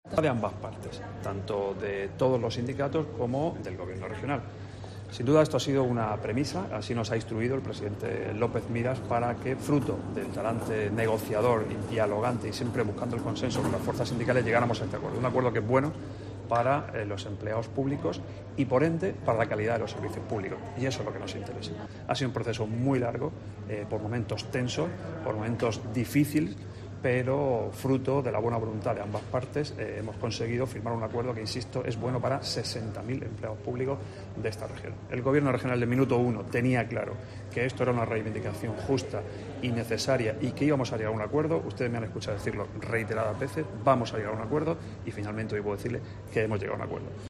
Luis Alberto Marín, consejero de Economía y Hacienda